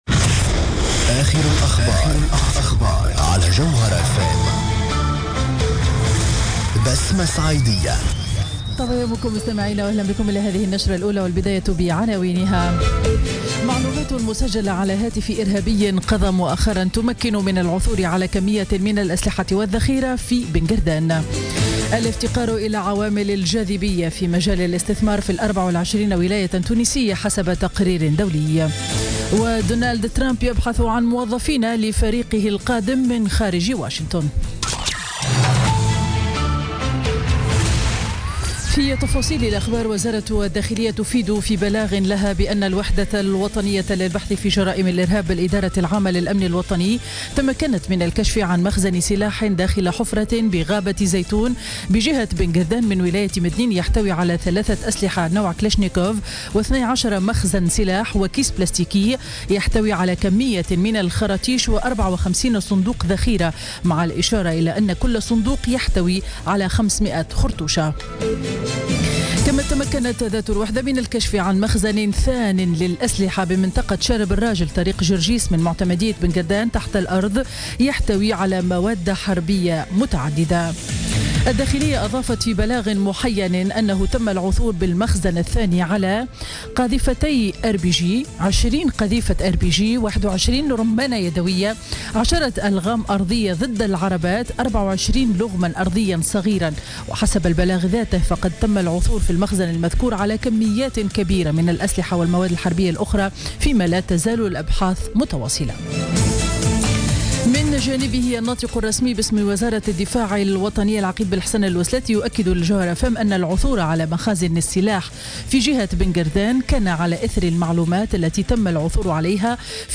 نشرة أخبار السابعة صباحا ليوم الأحد 13 نوفمبر 2016